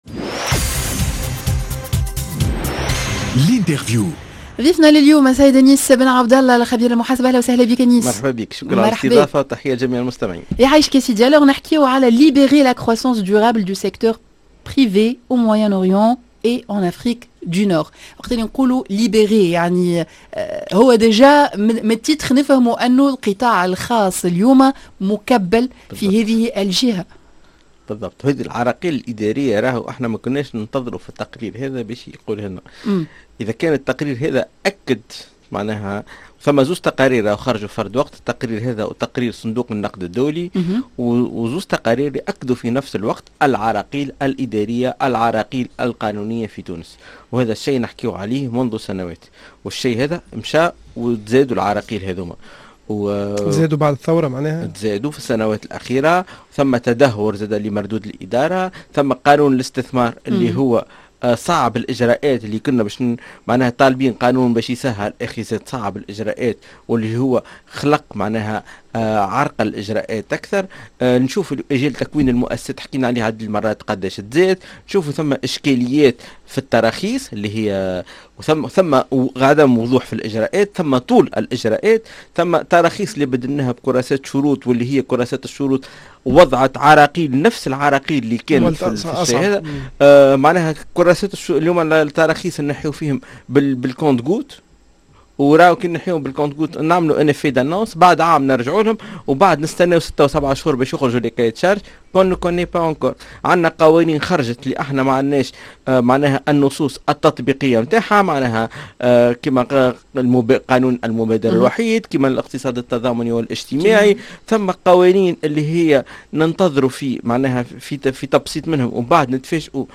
L'interview: دفع القطاع الخاصّ, هل هو رهين سياسات الدّولة